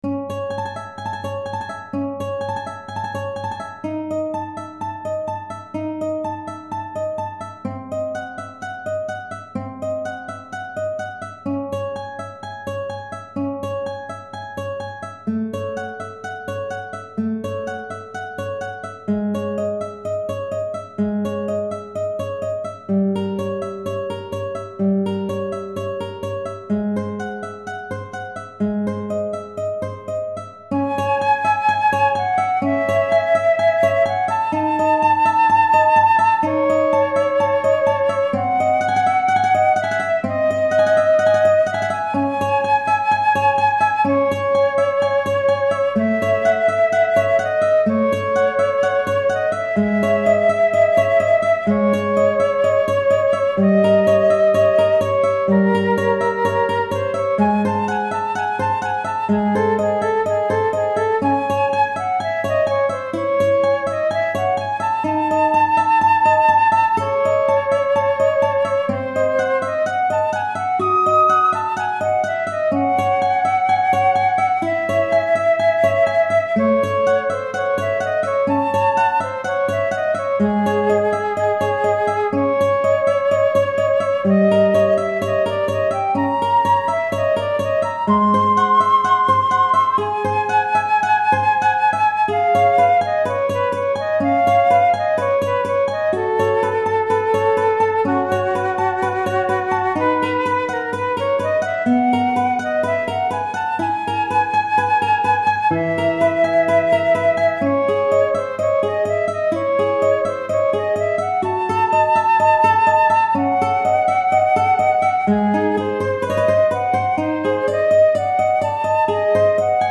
Flûte Traversière et Guitare